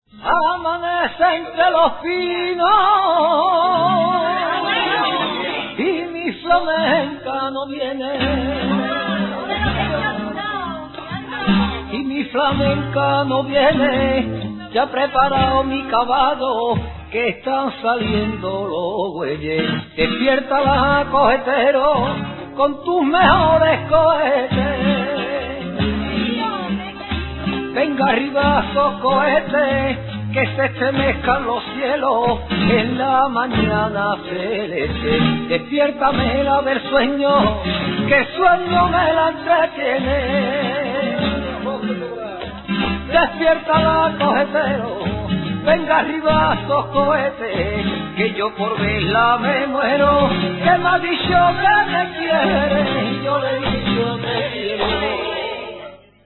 SEVILLANAS ROCIERAS